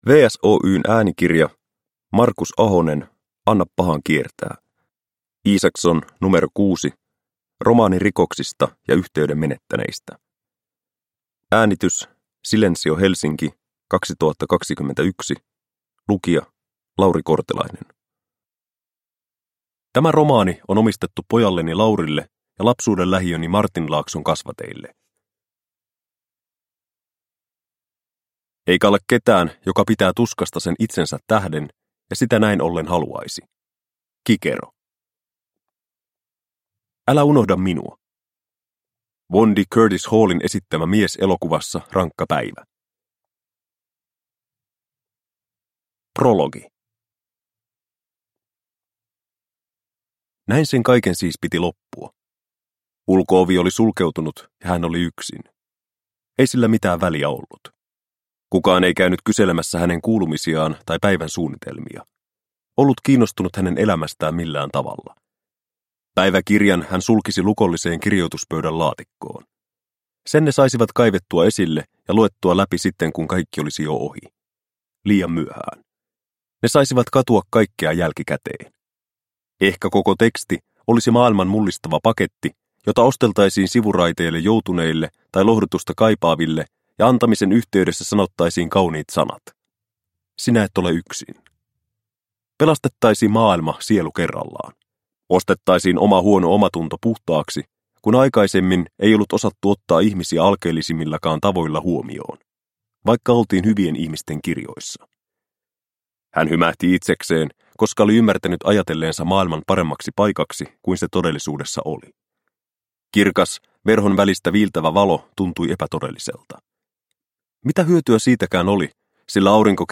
Anna pahan kiertää – Ljudbok – Laddas ner